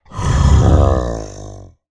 1.21.5 / assets / minecraft / sounds / mob / ravager / idle2.ogg